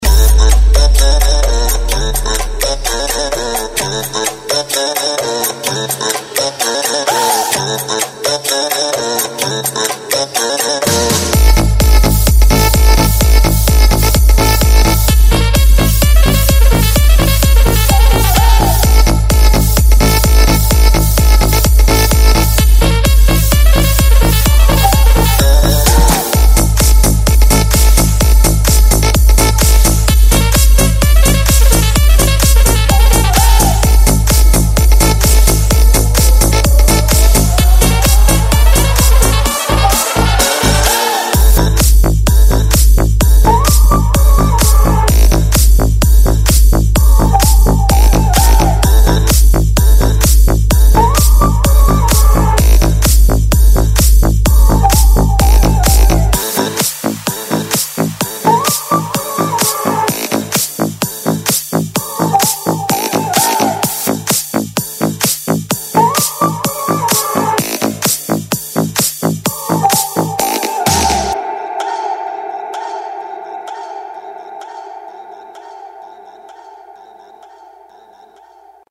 • Качество: 128, Stereo
клубный рингтон с восточными нотками